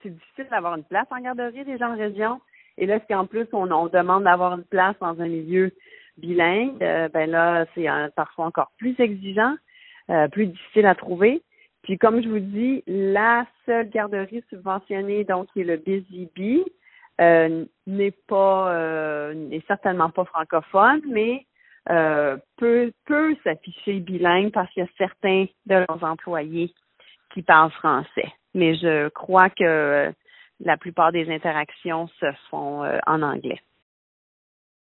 parent d’enfant.